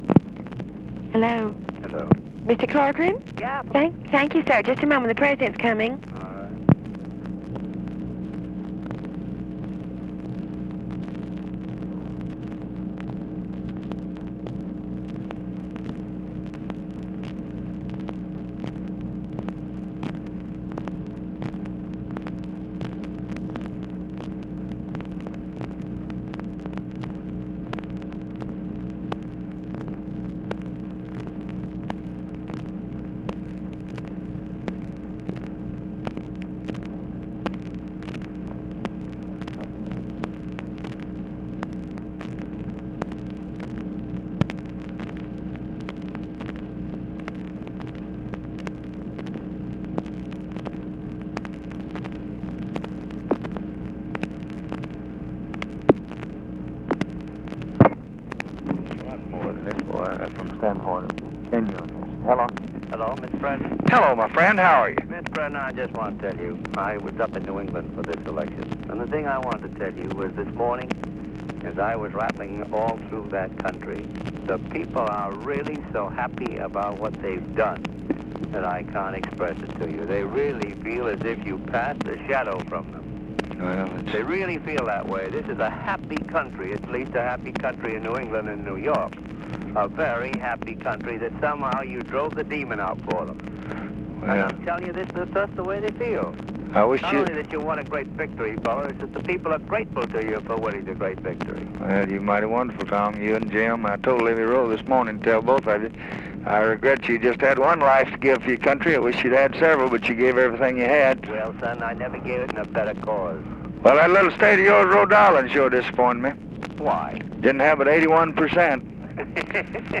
Conversation with TOMMY CORCORAN and HUBERT HUMPHREY, November 5, 1964
Secret White House Tapes